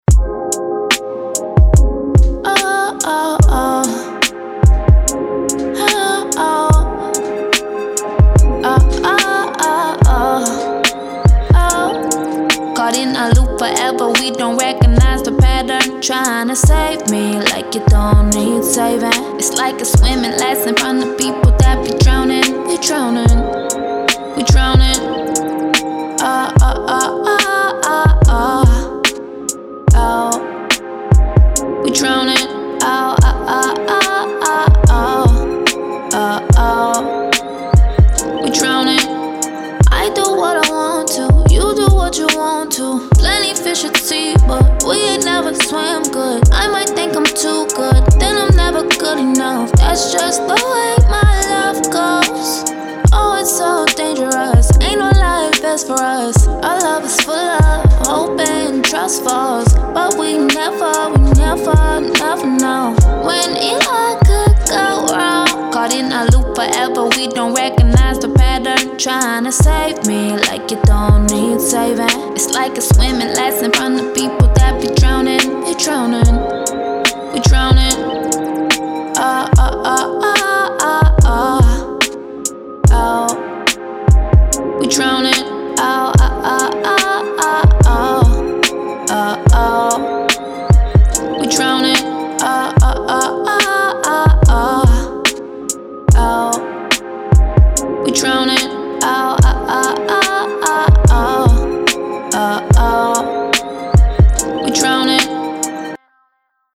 R&B
Ab Major